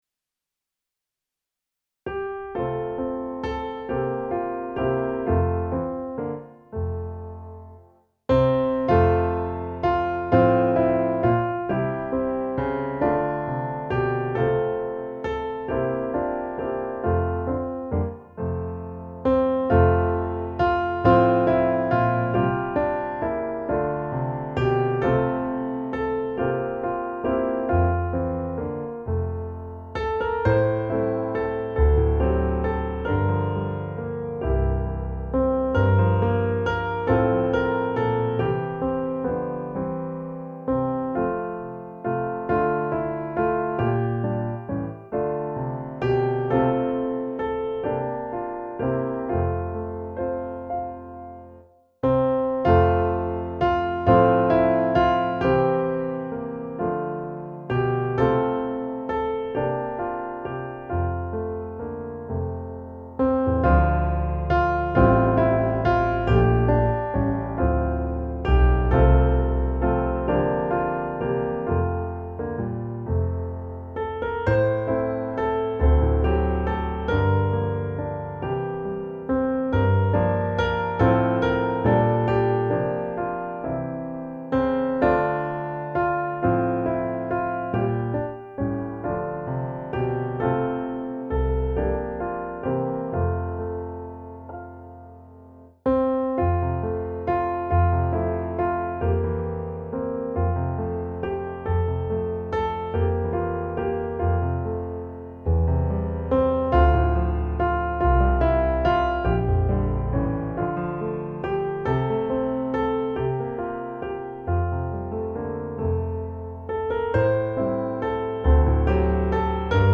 Det folk som vandrar i mörker - musikbakgrund
Musikbakgrund Psalm